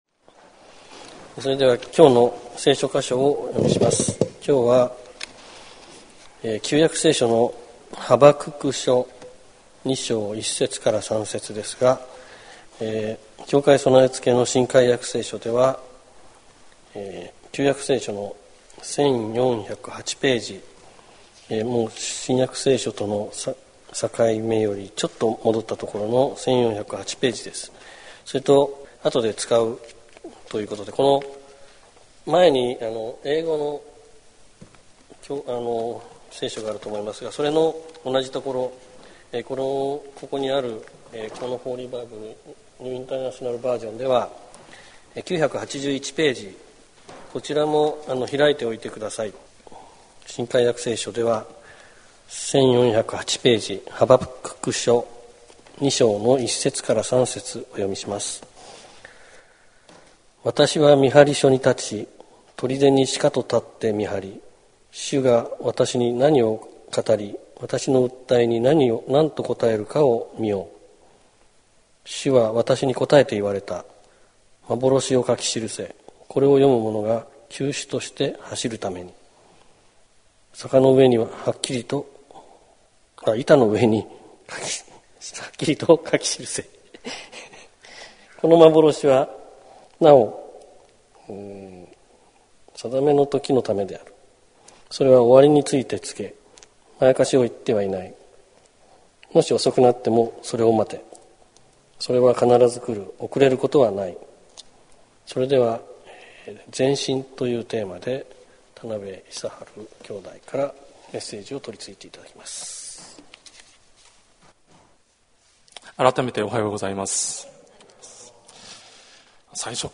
今朝、約一年ぶりにうちの教会の礼拝で説教を担当させていただいた。
相変わらず、早口になったり、つまったりじゃけど、全く意味が解らんっつうこともねぇと思う。